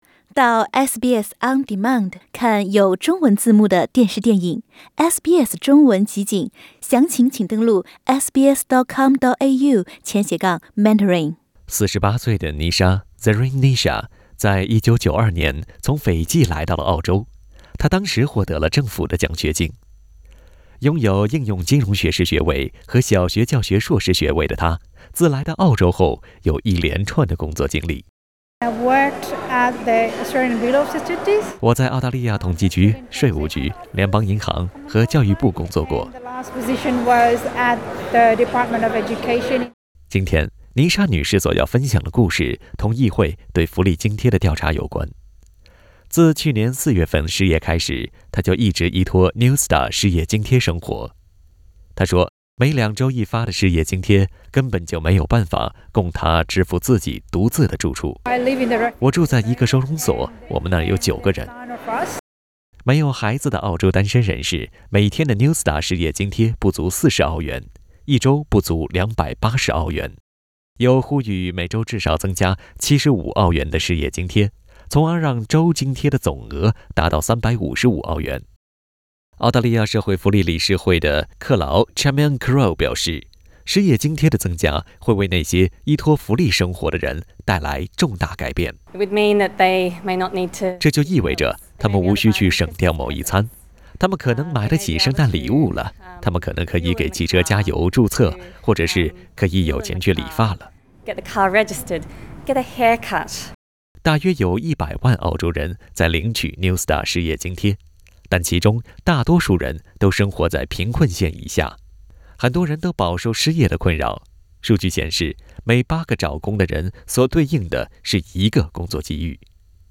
點擊音頻，收聽本台記者為您帶來的詳細報道。